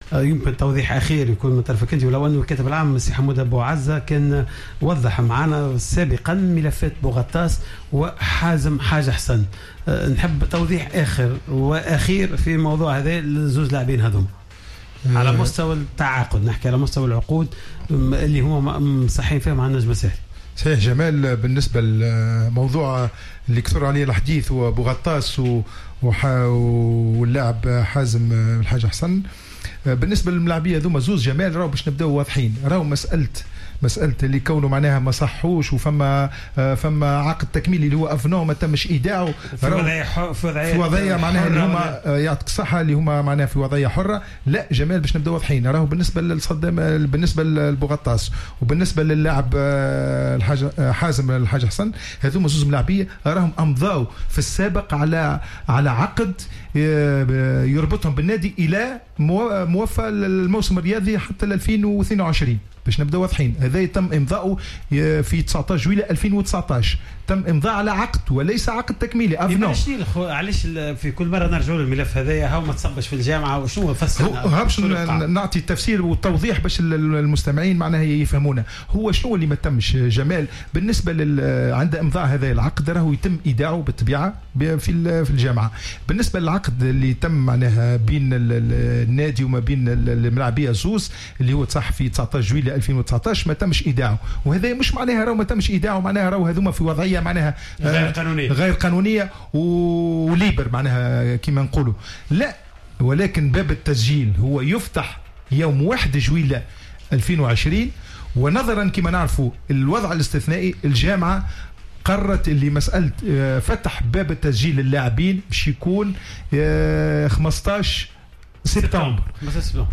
لدى حضوره في حصة "راديو سبور"